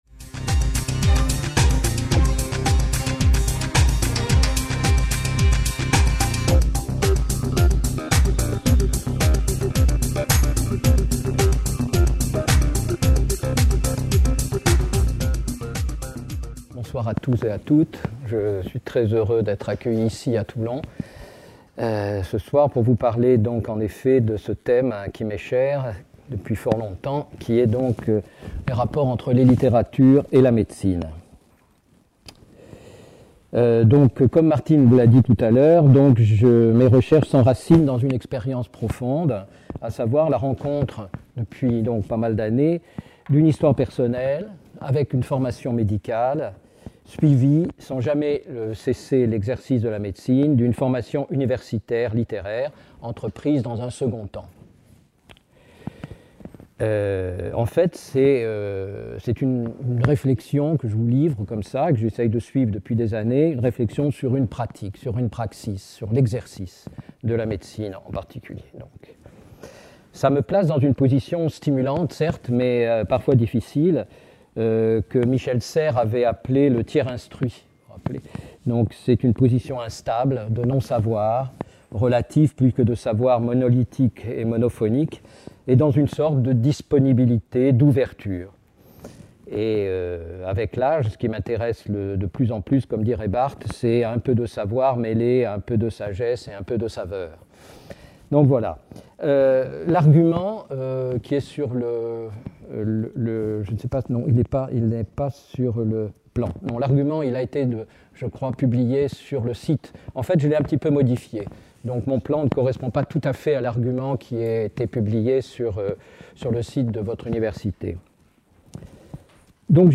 Conférence "Littérature et médecine" | Canal U
Conférence organisée le 5 février 2015, par le laboratoire Babel de l'Université de Toulon sur le campus de La Garde.